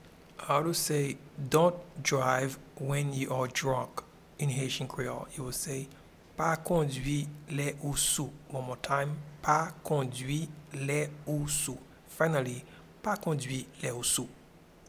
Pronunciation and Transcript:
Dont-drive-when-you-are-drunk-in-Haitian-Creole-Pa-kondwi-le-ou-sou.mp3